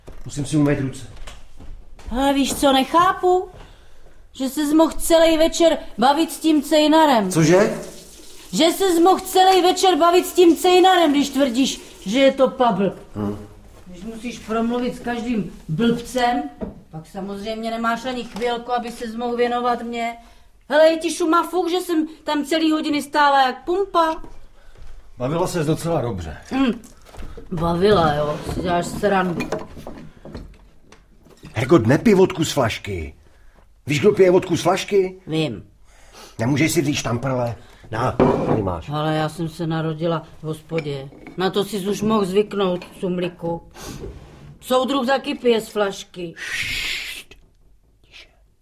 Ucho audiokniha